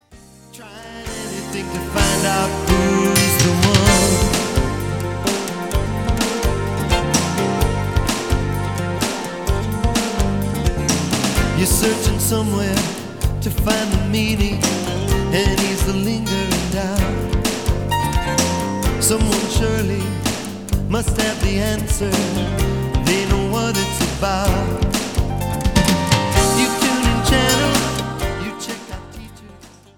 Songs on liturgical themes.
beautiful expression of contemporary, Jewish-American music.